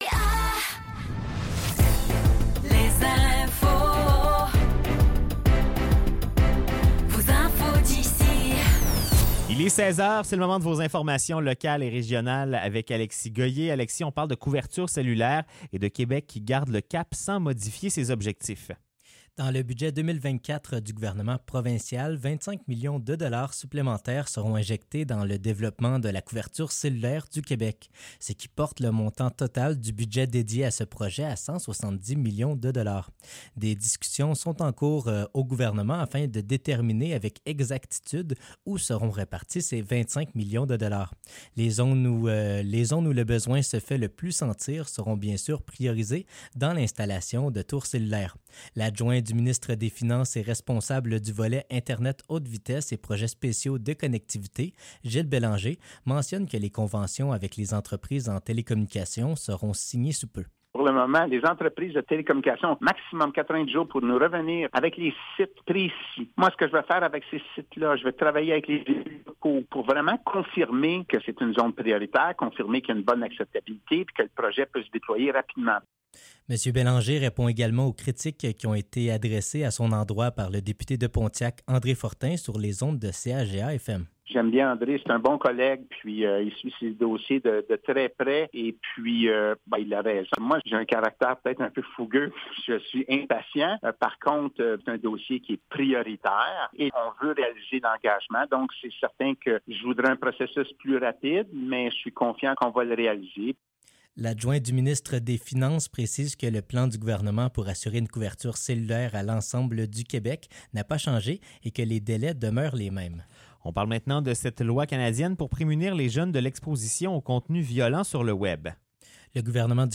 Nouvelles locales - 19 mars 2024 - 16 h